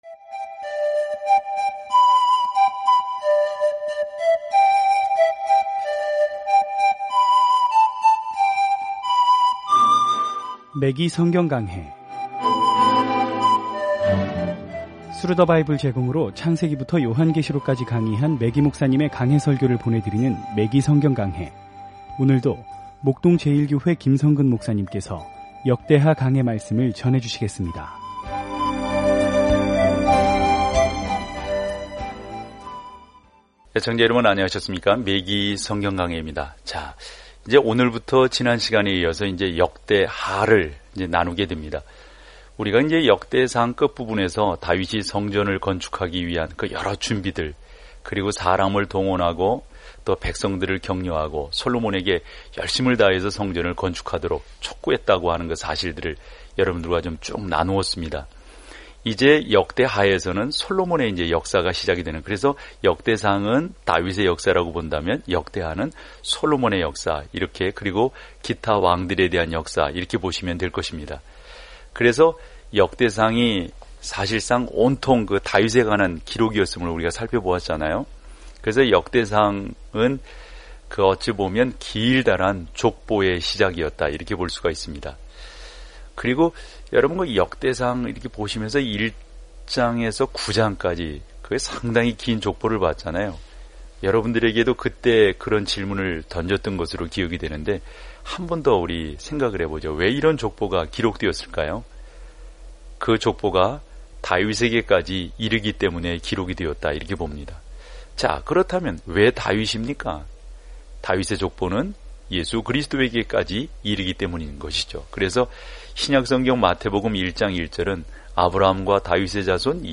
말씀 역대하 1 묵상 계획 시작 2 묵상 소개 역대하에서 우리는 이스라엘의 과거 왕들과 선지자들에 관해 들었던 이야기들에 대해 다른 관점을 갖게 됩니다. 오디오 공부를 듣고 하나님의 말씀에서 선택한 구절을 읽으면서 매일 역대하를 여행하세요.